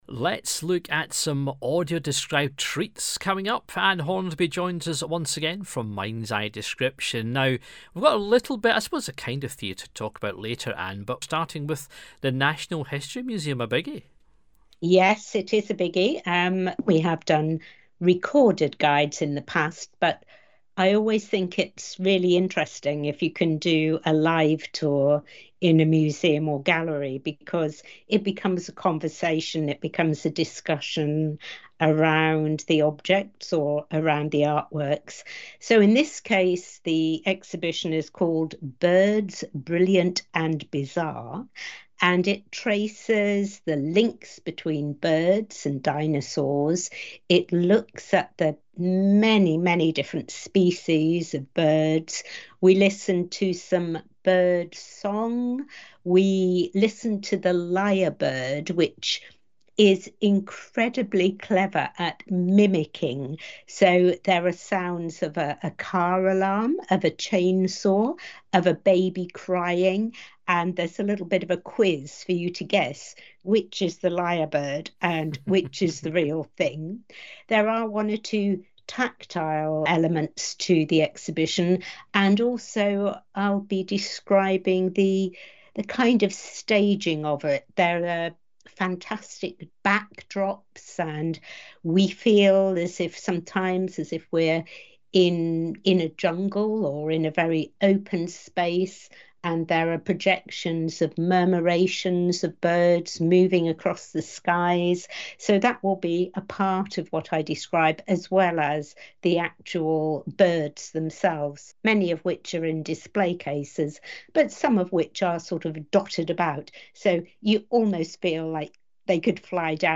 Studio Recording